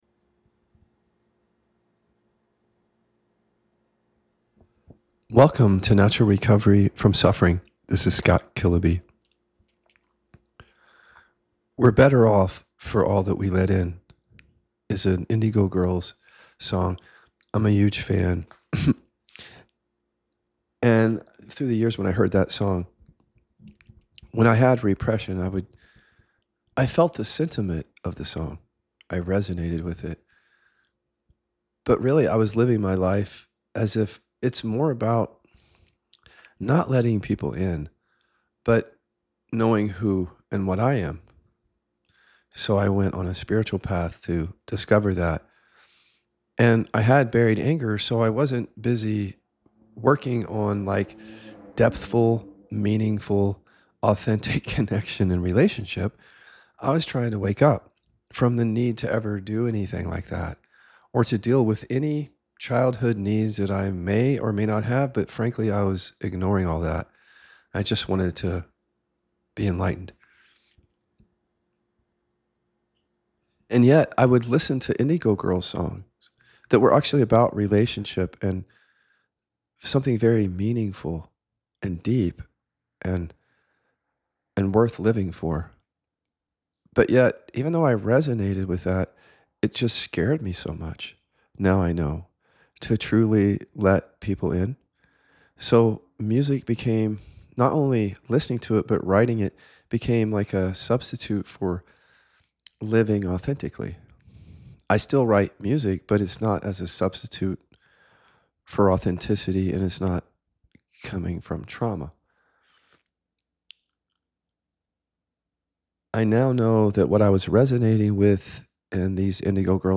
speaks directly and unapologetically